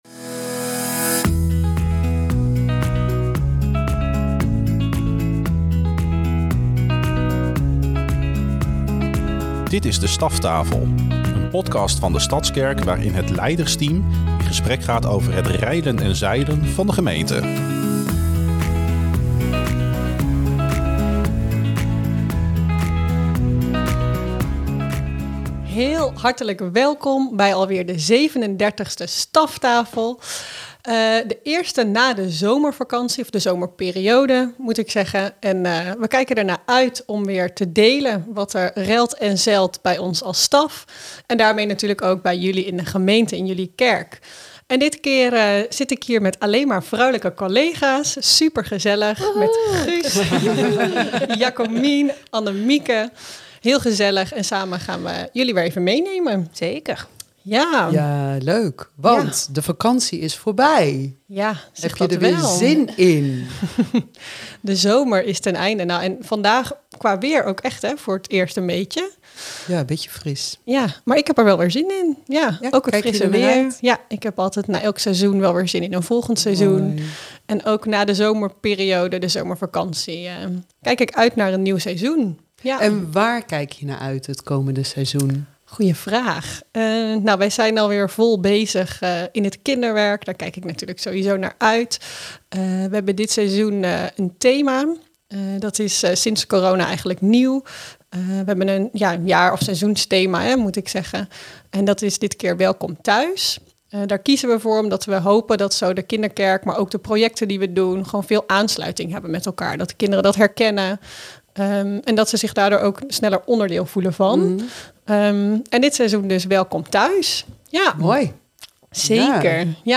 Voor het eerst in de geschiedenis van De Staftafel zitten er vier vrouwen aan tafel.
ACHTERGROND: Een keer in de maand gaan de stafleden met elkaar in gesprek over het reilen en zeilen van de gemeente.